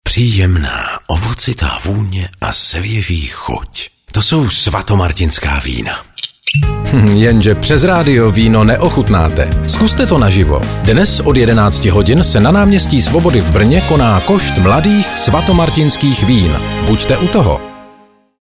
Typ média: rozhlasová reklama
SVATOMARTINSKE_Event_Brno_radio.mp3